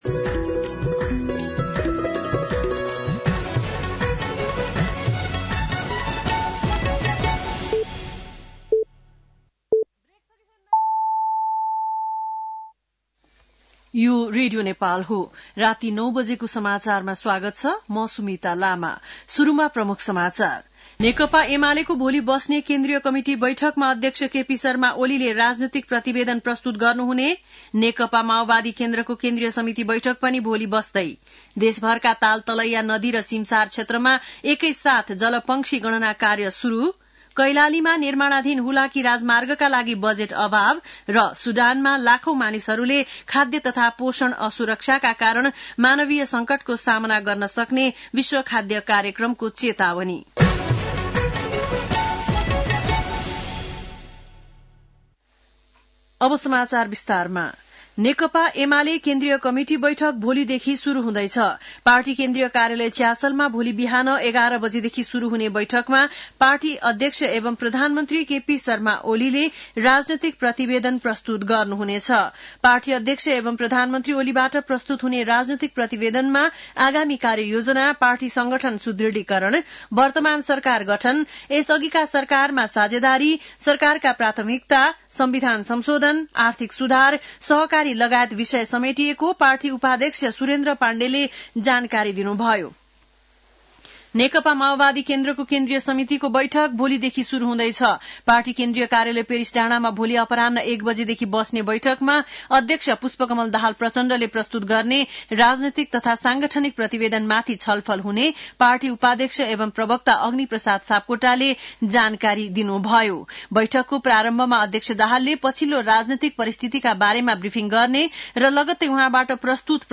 बेलुकी ९ बजेको नेपाली समाचार : २१ पुष , २०८१